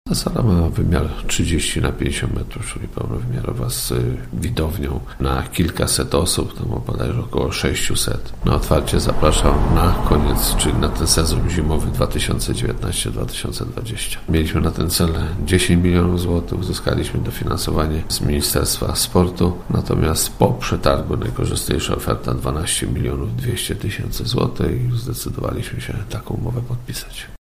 – Duże wydarzenia kulturalne także chcielibyśmy organizować w takim właśnie obiekcie – mówi Wiesław Czyczerski, burmistrz Zbąszynka.